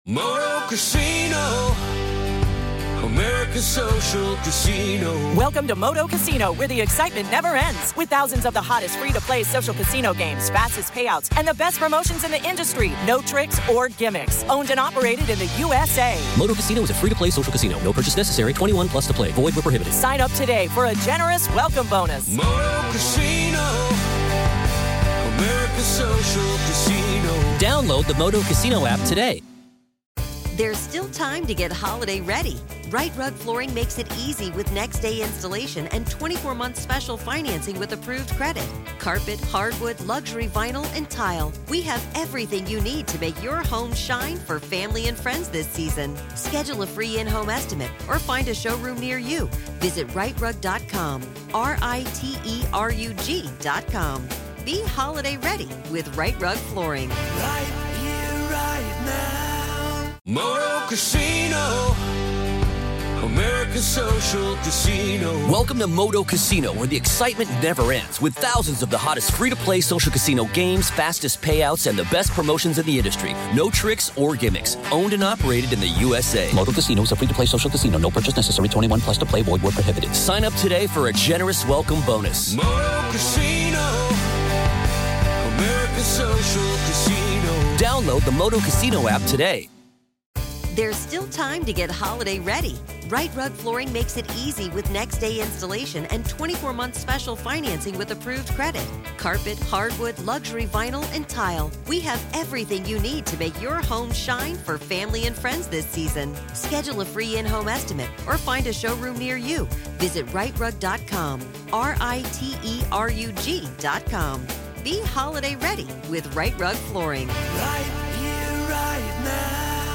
In this fascinating conversation